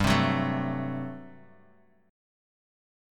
GM7sus2sus4 Chord